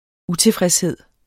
Udtale [ ˈuteˌfʁεsˌheðˀ ]